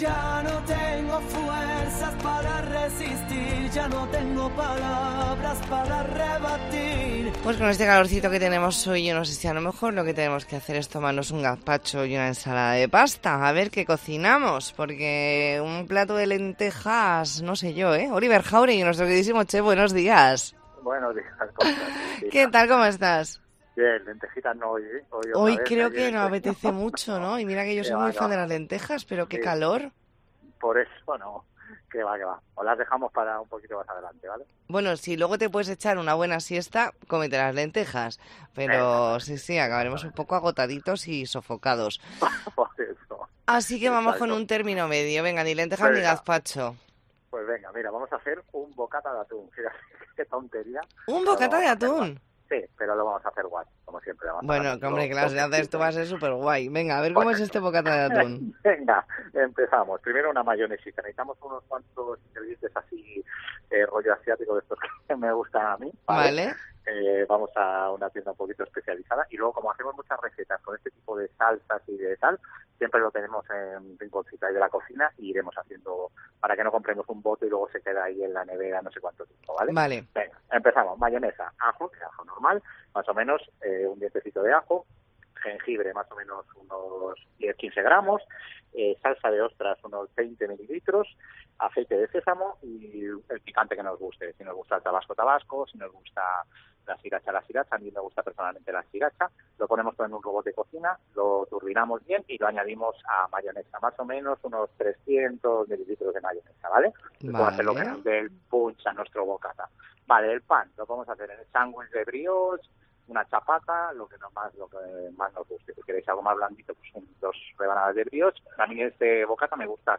Entrevista en La Mañana en COPE Más Mallorca, lunes 13 de noviembre de 2023.